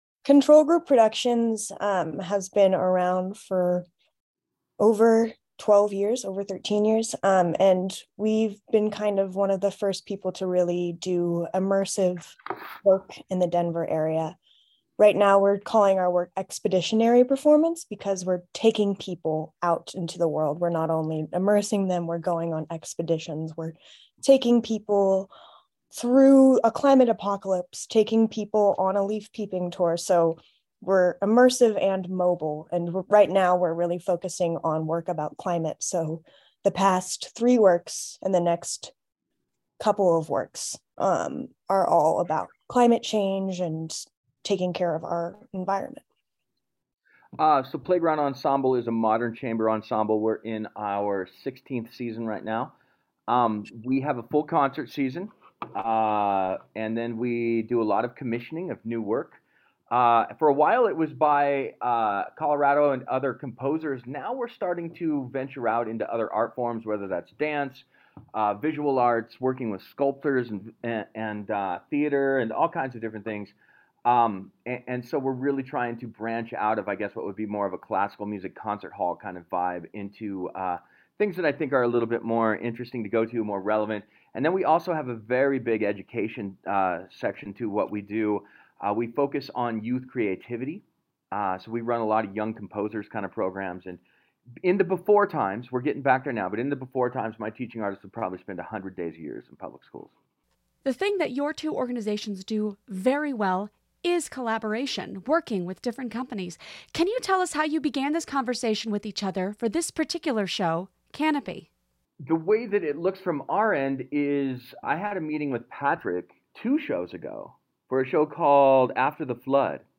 Control-Group-Playground-Final-interview.mp3